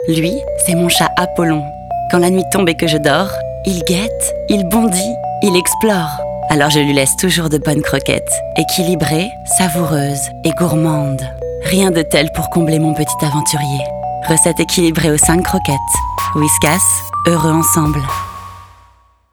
voix off publicité
35 - 45 ans - Mezzo-soprano